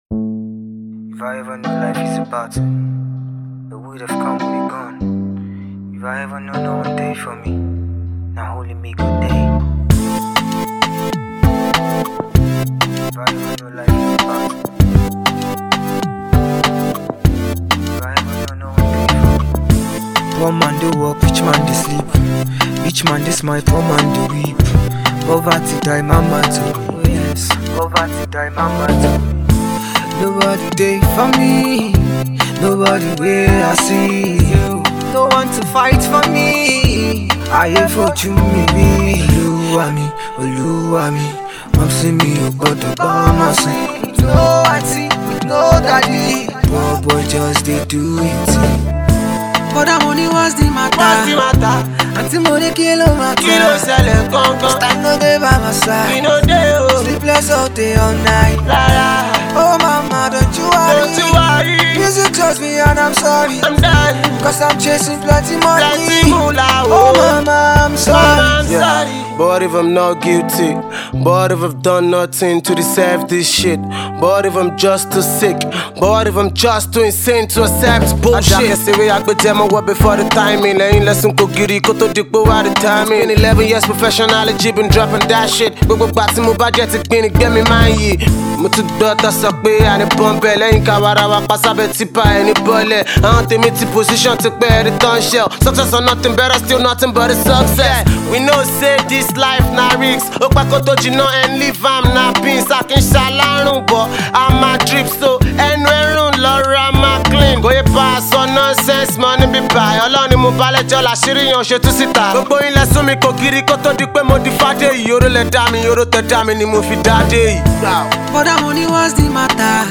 afro pop
melodious tune
highly motivational sound